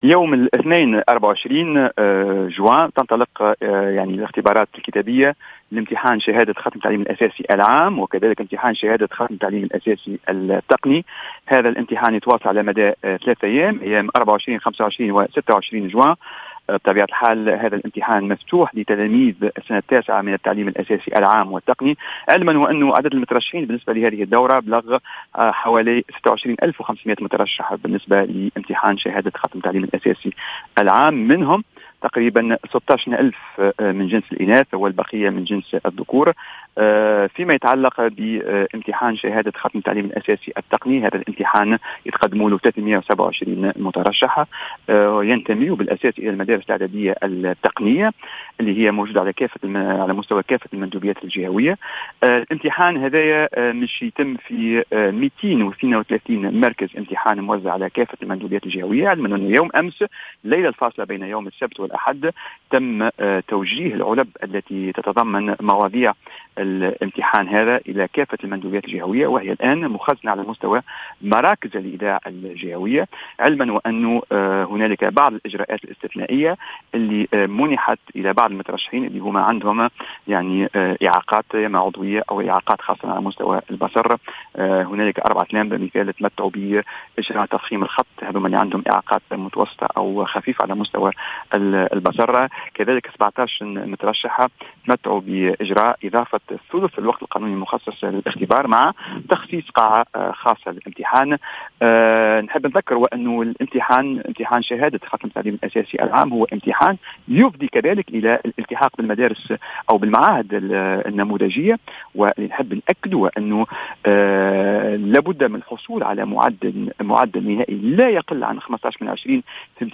قال المدير العام للامتحانات، عمر الولباني، في تصريح لـ "الجوهرة أف أم" اليوم إن الاختبارات الكتابية لامتحان شهادة ختم تعليم أساسي بالنسبة للعام والتقني والمعروفة بـ "النوفيام"، تنطلق بداية من يوم غد الاثنين على امتداد ايام 24 و25 و26 جوان 2019. وأفاد بأن عدد المترشحين بلغ 26.500 ألف مترشّح، 16 ألف منهم من جنس الاناث، مشيرا إلى أن عدد المترشحين بالنسبة للتقني بلغ 327 مترشحا من المدارس الإعدادية التقنية.